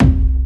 • 00s Subby Kick C Key 618.wav
Royality free bass drum single hit tuned to the C note. Loudest frequency: 237Hz
00s-subby-kick-c-key-618-MFa.wav